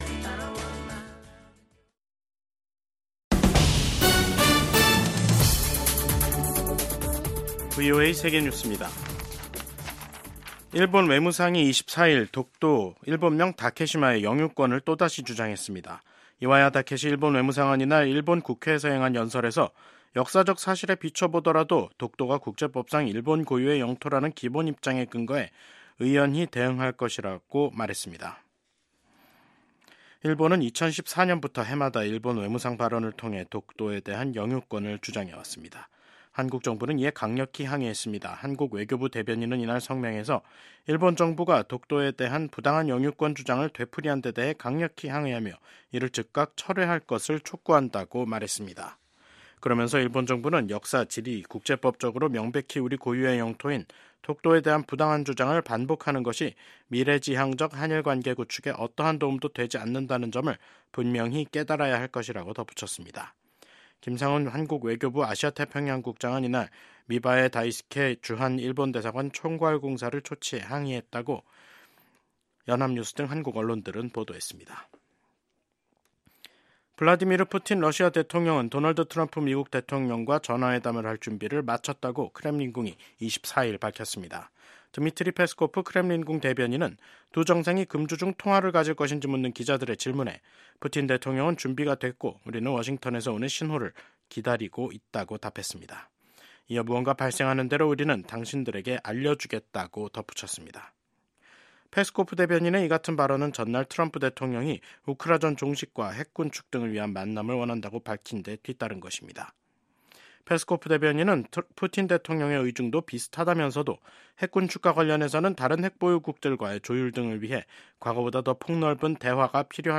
VOA 한국어 간판 뉴스 프로그램 '뉴스 투데이', 2025년 1월 24일 3부 방송입니다. 도널드 트럼프 미국 대통령이 김정은 북한 국무위원장과 다시 만날 것이라는 의지를 밝혔습니다. 미국 전문가들은 미북 정상회담이 열릴 경우 북한 안전 보장과 대북 제재 해제를 대가로 추가 핵 실험과 미사일 발사 중단 등 북한 핵 동결이 논의될 수 있을 것으로 전망했습니다. 북한은 이틀간 최고인민회의를 열었지만 미국과 한국에 대한 메시지를 내놓지 않았습니다.